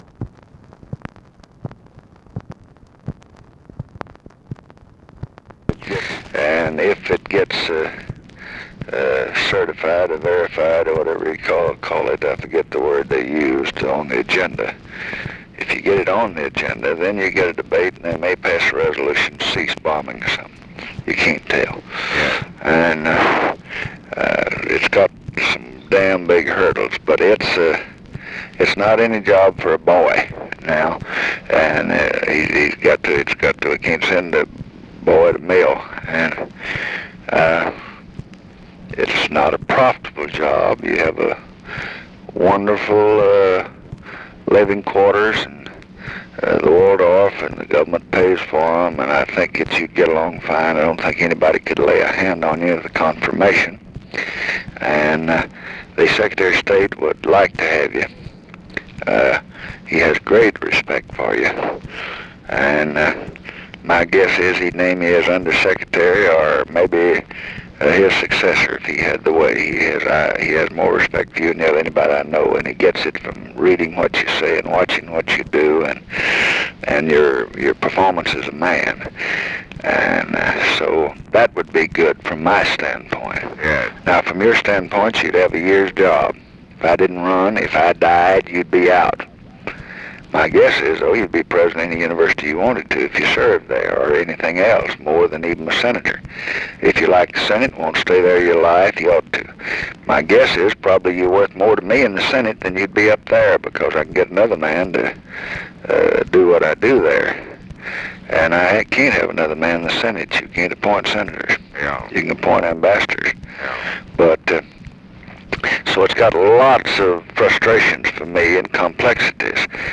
Conversation with GALE MCGEE, December 9, 1967
Secret White House Tapes